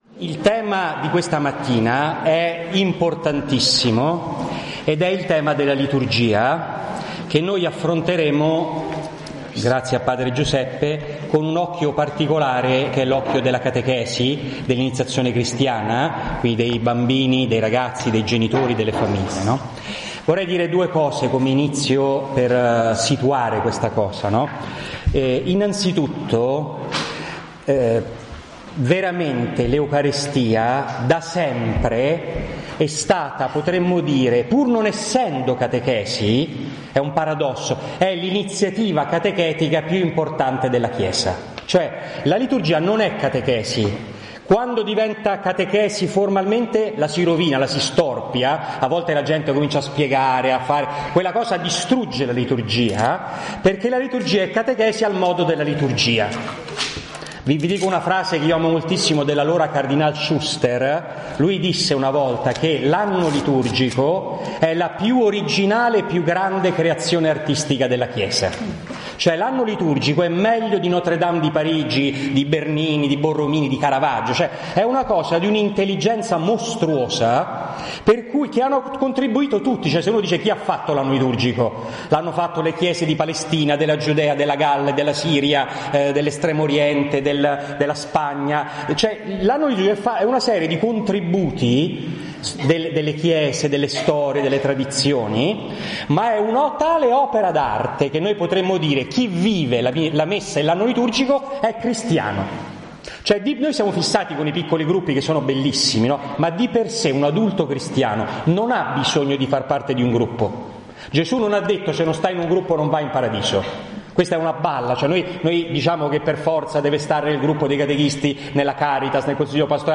Mettiamo a disposizione sul nostro sito i file audio delle relazioni tenute in occasione dello stage di formazione per catechisti organizzato dall'Ufficio catechistico della diocesi di Roma nei giorni 18-20 settembre 2015, presso la Fraterna Domus d Sacrofano.